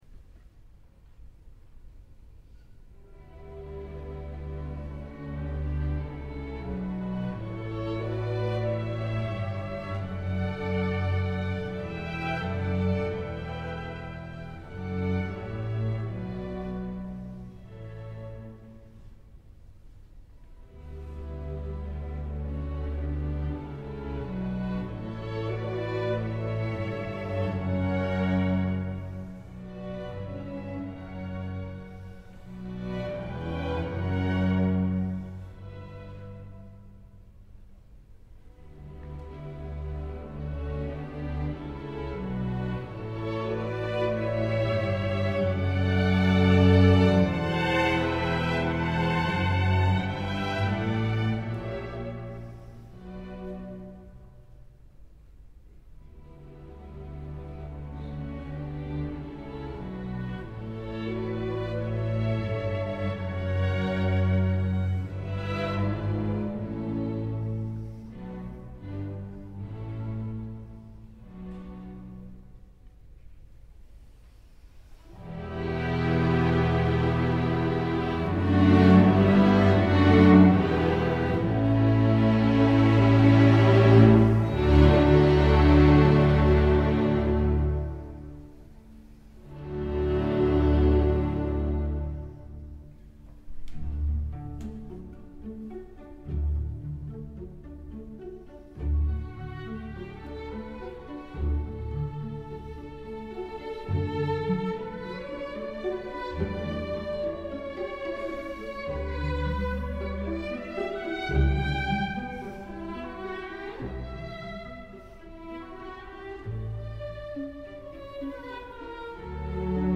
Yaylı Çalgılar İçin Serenat
(Yavaş Bölüm)